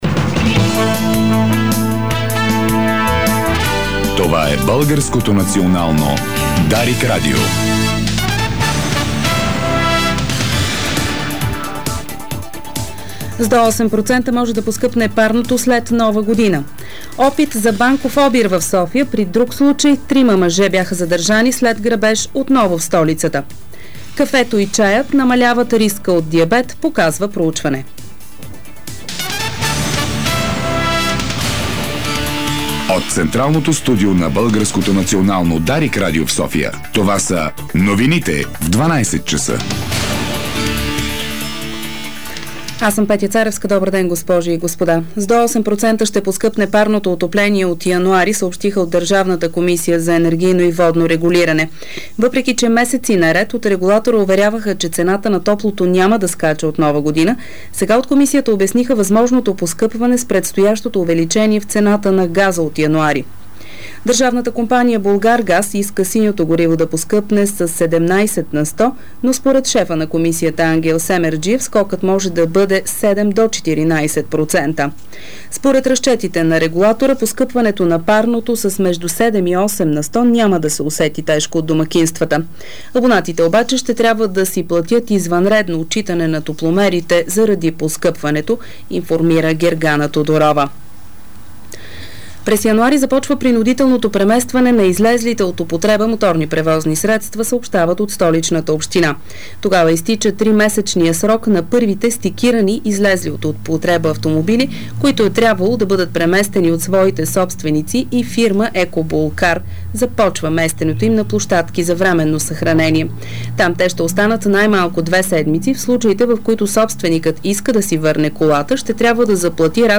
Обедна информационна емисия - 15.12.2009